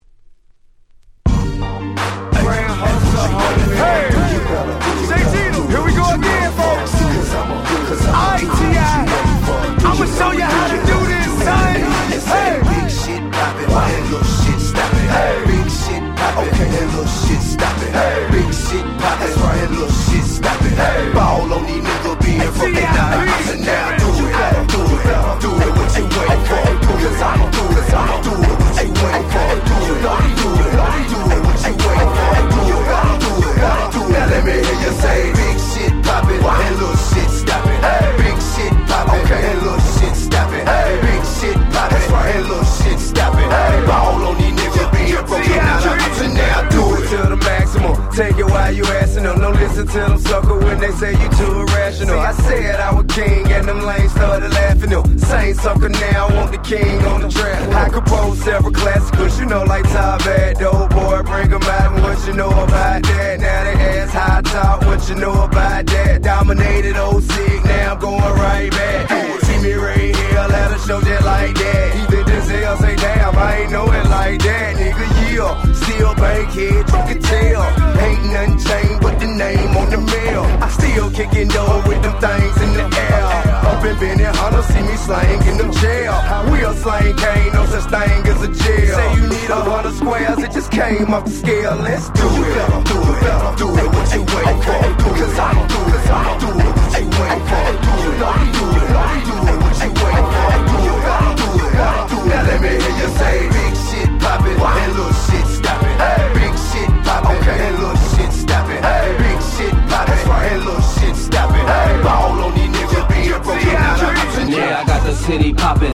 08' Nice Southern Hip Hop !!